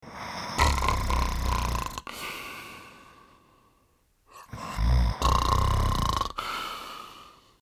B_RONFLE.mp3